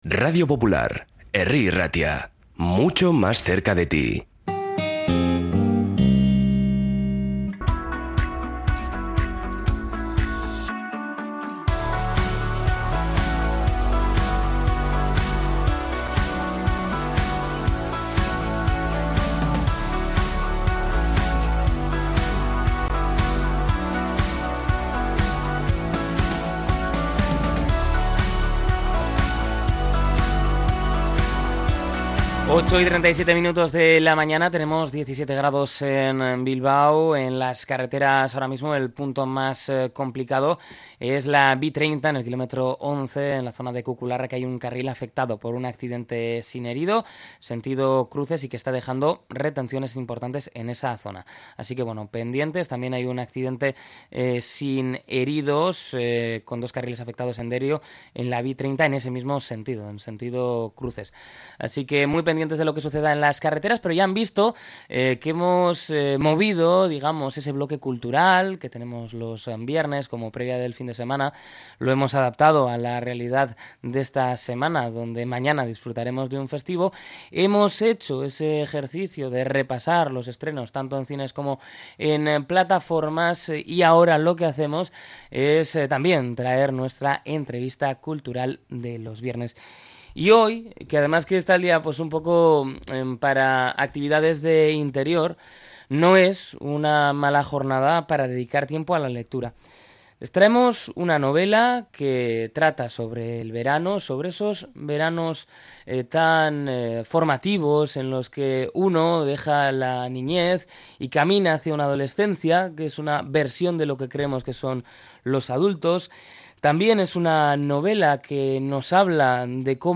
La novela ha sido protagonista de la entrevista cultural de EgunOn Bizkaia